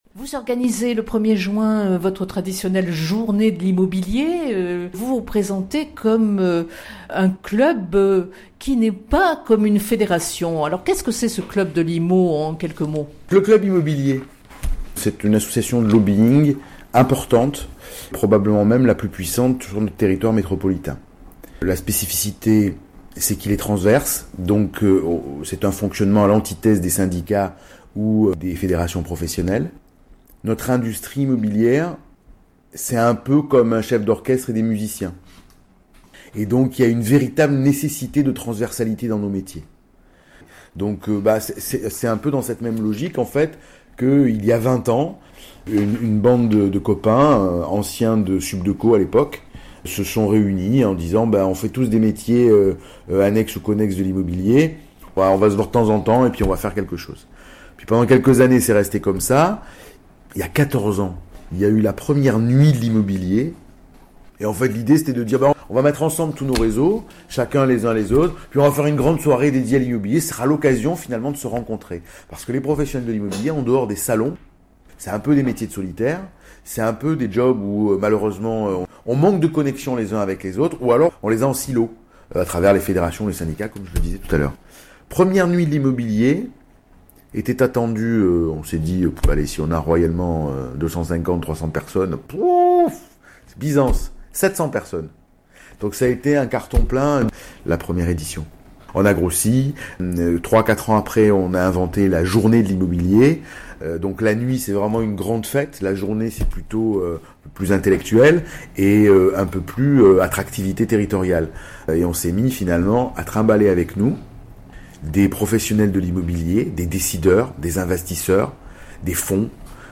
Journée de l’Immobilier à Marseille : Entretien